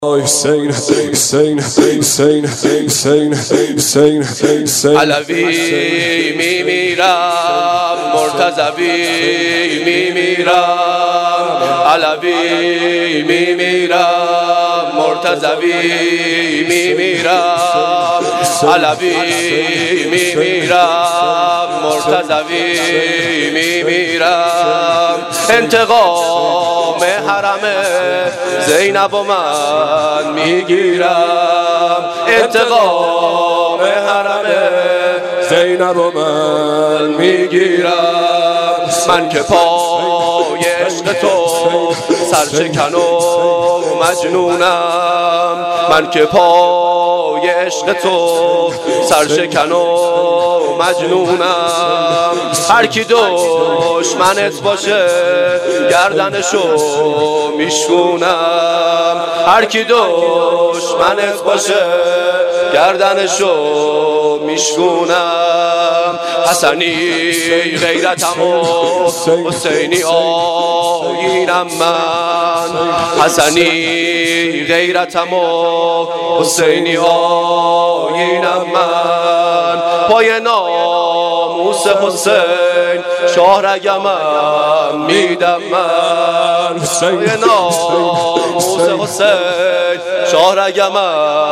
اجرا شده در هیئت جواد الائمه (علیه السلام) کمیجان
شور